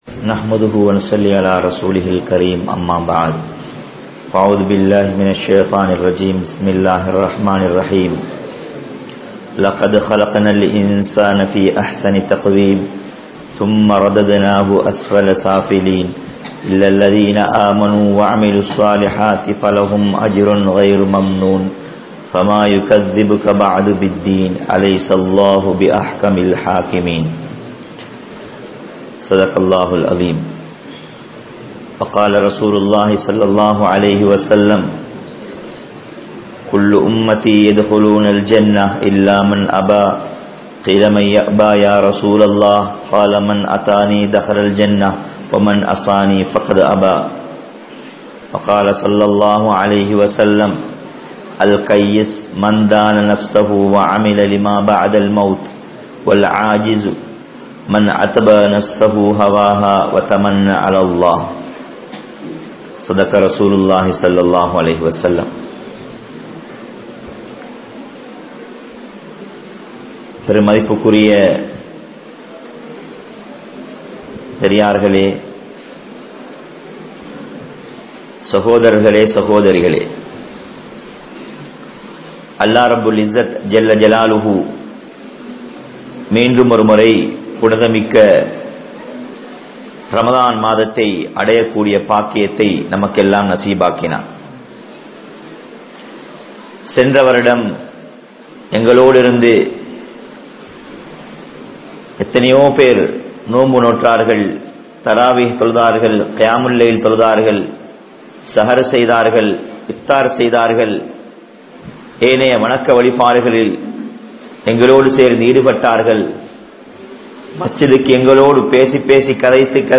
Allahvin Mannitppu (அல்லாஹ்வின் மன்னிப்பு) | Audio Bayans | All Ceylon Muslim Youth Community | Addalaichenai
Kurunegala, Mallawapitiya, Masjidhul Hasanath